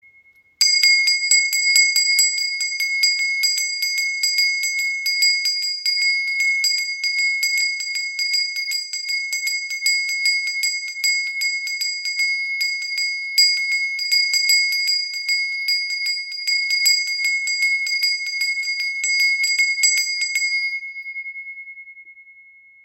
• Icon Klarer Klang – Füllt den Raum mit Ruhe und Harmonie
Tempelglocke mit Pfaufigur · Handgefertigt aus Rishikesh · 02
Ihr klang verbreitet eine harmonische Atmosphäre und eignet sich perfekt für Rituale und Meditationen.
• Material: Massives Messing